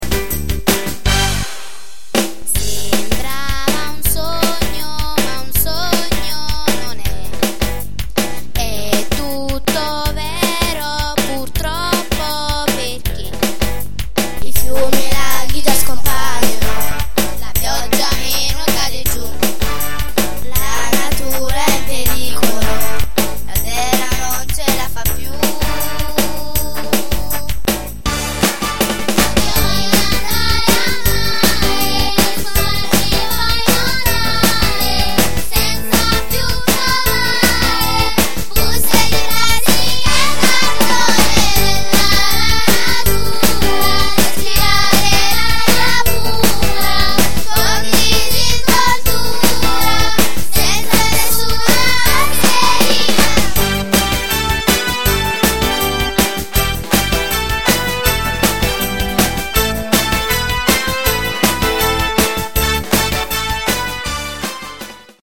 ASCOLTA LA CANZONE ( Bambini)